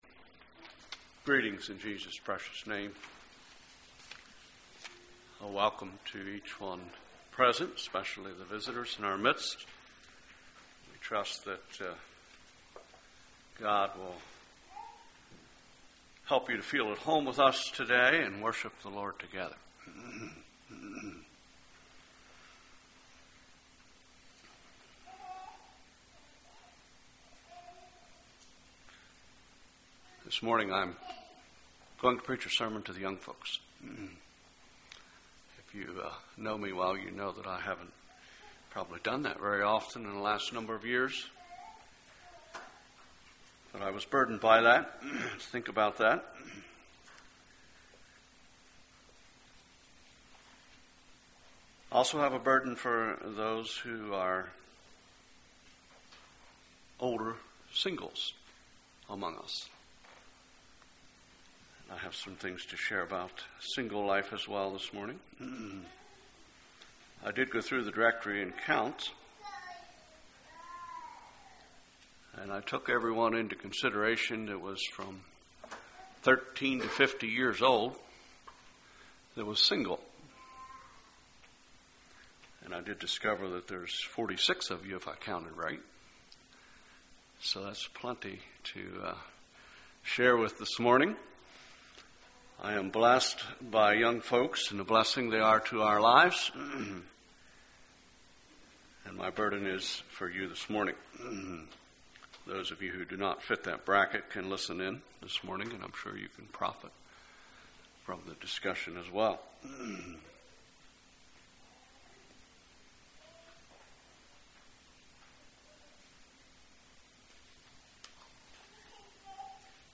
Congregation: Ridge View Speaker